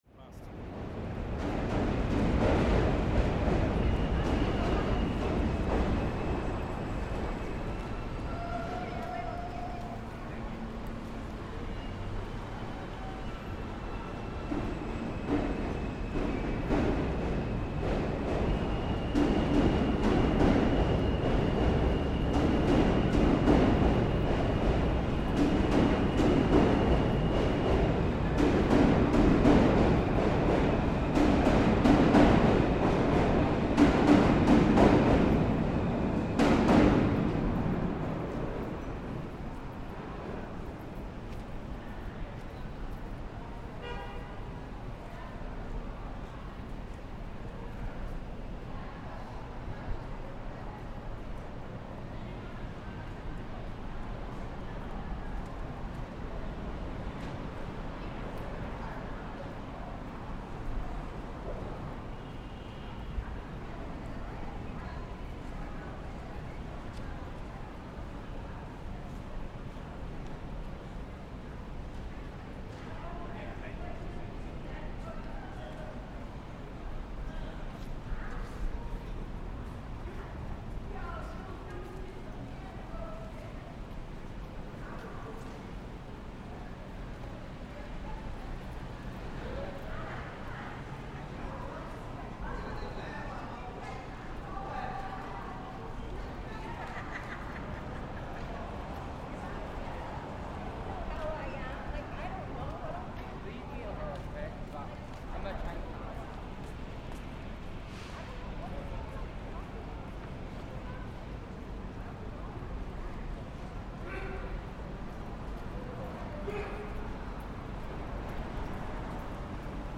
Trains passing overhead in Chinatown
Beneath the bridge at Division Street in Chinatown, we're waiting for trains to pass overhead, reverberating through the huge concrete structure.